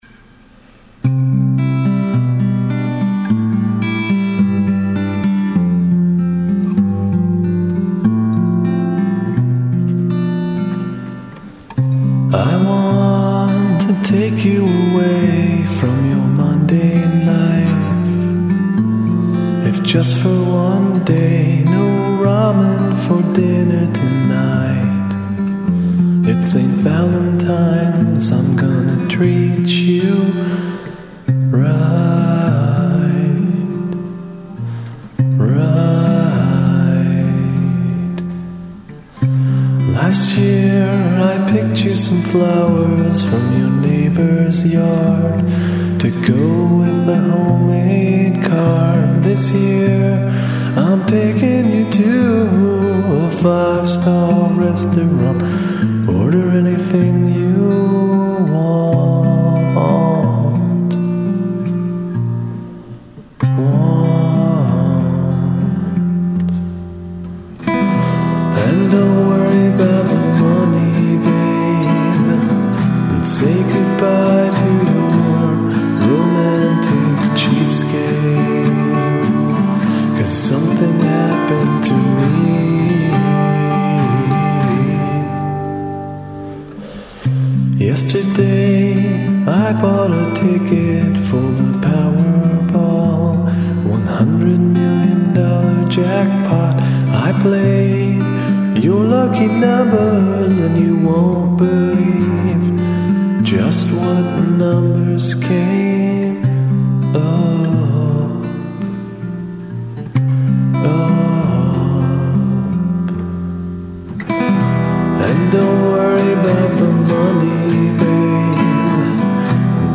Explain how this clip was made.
There is a crappy demo of it here: